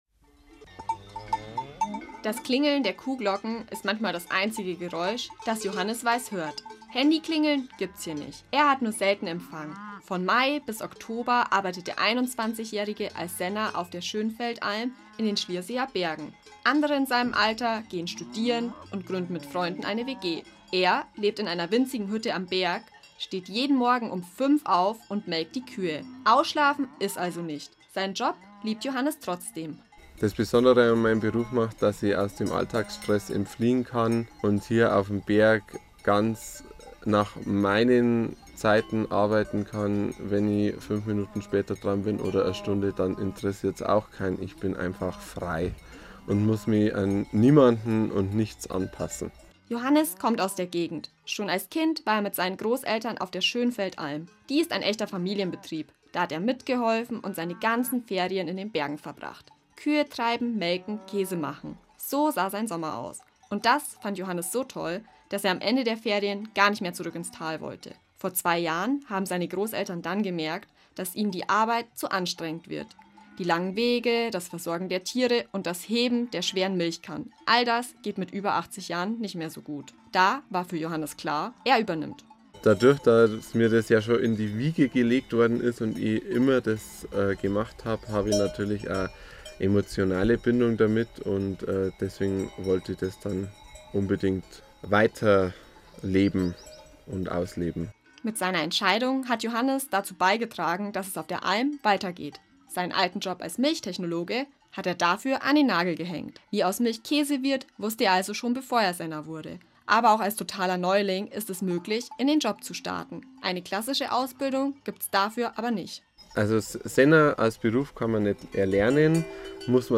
Zusätzlich haben wir für das Bayern 2 Rucksackradio Beiträge produziert, die von unseren „Wie werde ich...?“-Berufen am Berg erzählen.